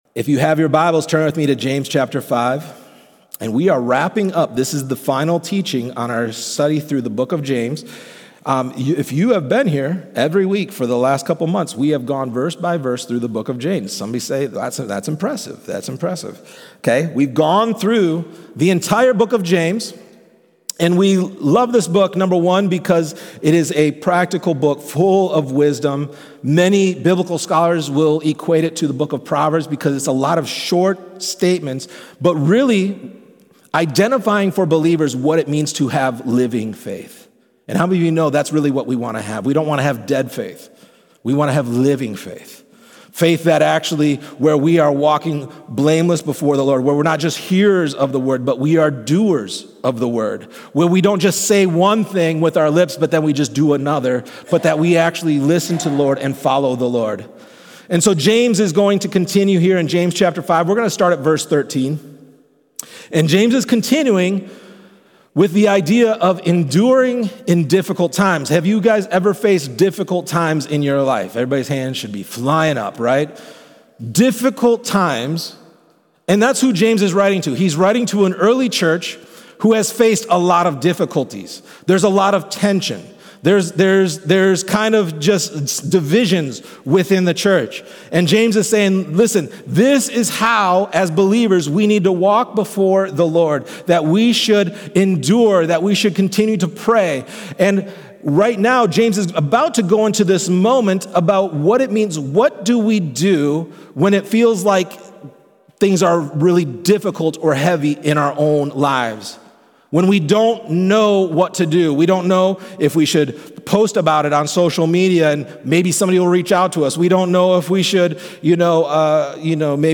In this sermon, you'll discover how prayer isn’t a last resort but our first response. Whether we’re struggling physically, emotionally, or spiritually, God invites us to bring it all to Him in faith.